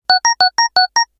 powerUp1.ogg